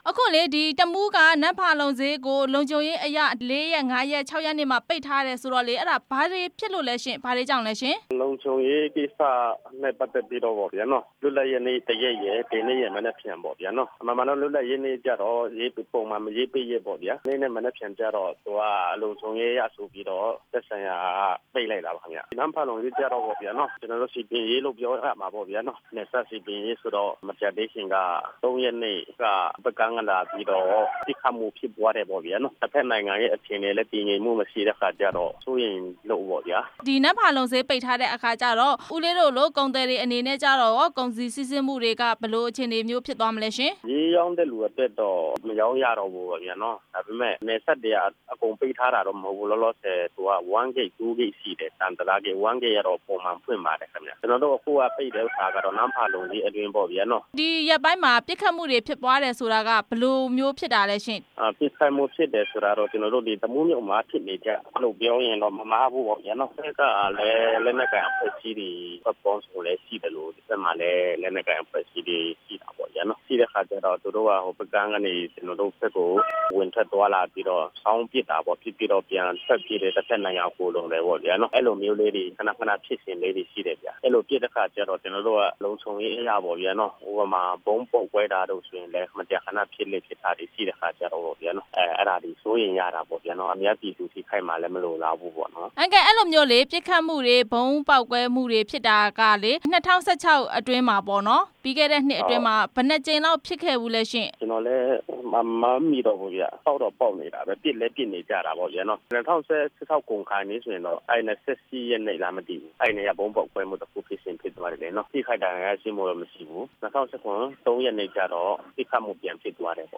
ဒေသခံ ကုန်သည်တစ်ယောက်ဖြစ်တဲ့